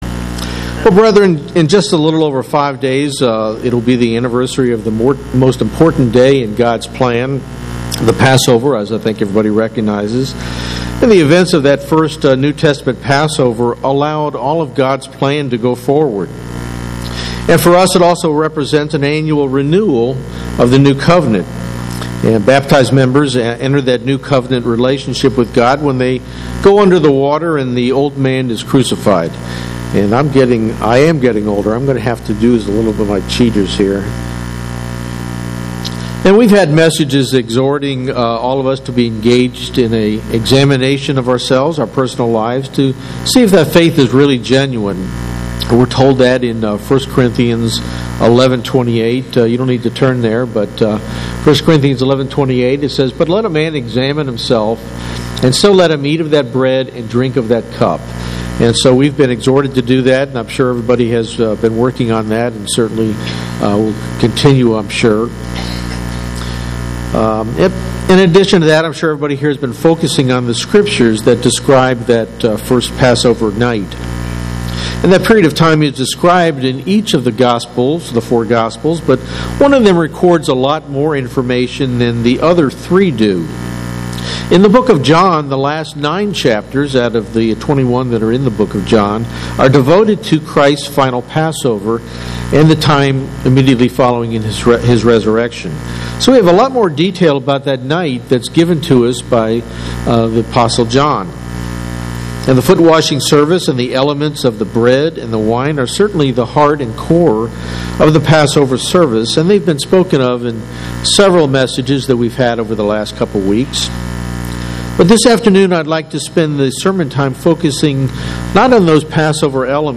Immediately following His introduction of the New Testament Passover elements, Christ spoke at length to His disciples and prayed for them. This sermon covers portions of chapters 13 through 17 in the Gospel of John.
Given in Atlanta, GA